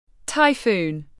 Typhoon /taɪˈfuːn/